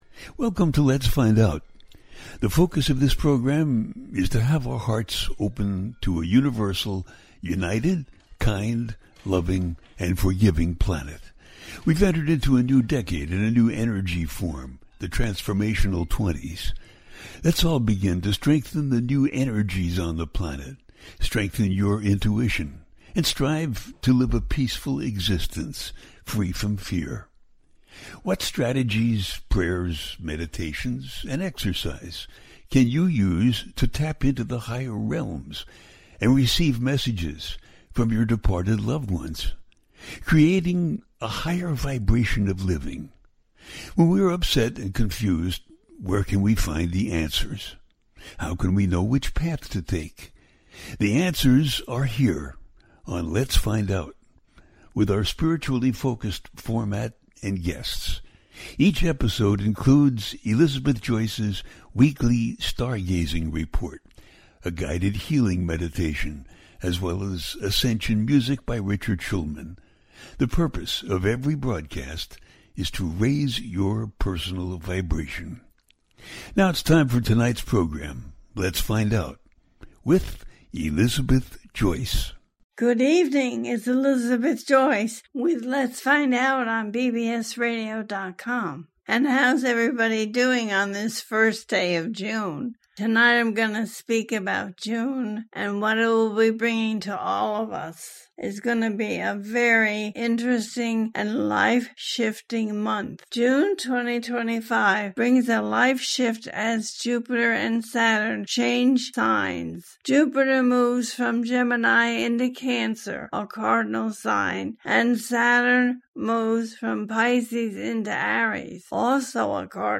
June 2025 The Month Of Completion And Preparation - A teaching show
The listener can call in to ask a question on the air.
Each show ends with a guided meditation.